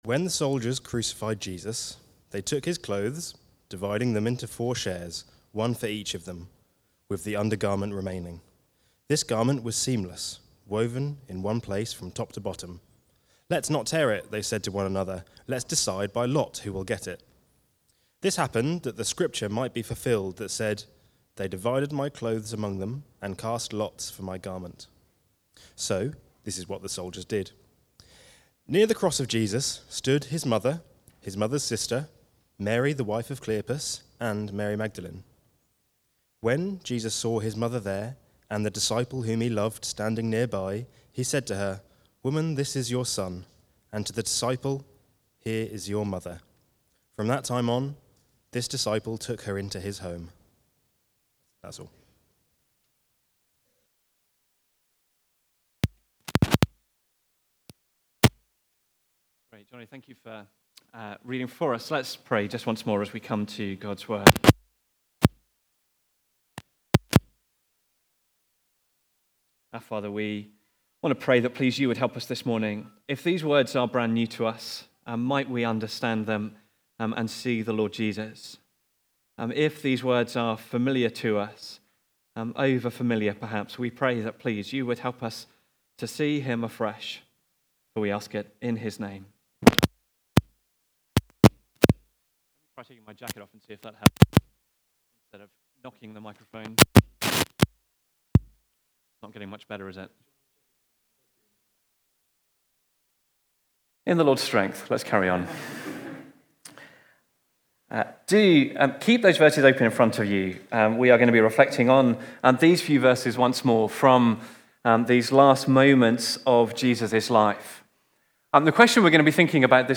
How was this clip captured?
The Bystanders (John 19:23-27) from the series Life From Death. Recorded at Woodstock Road Baptist Church on 15 March 2026.